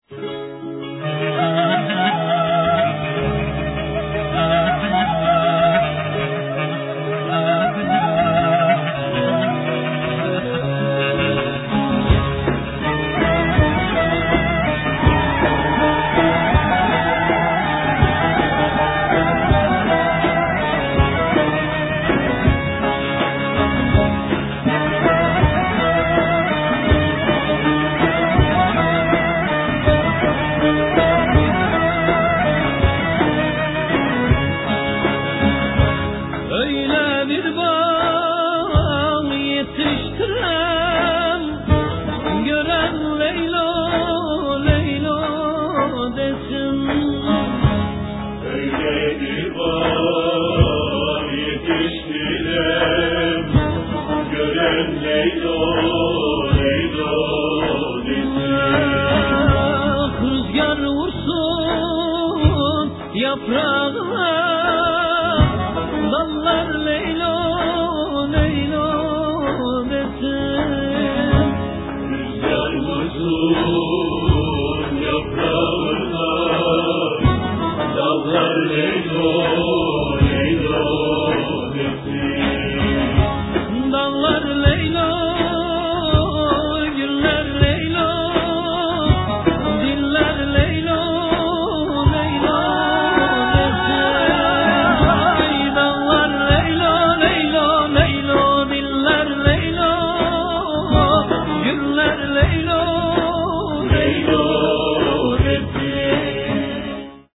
turecká píseň: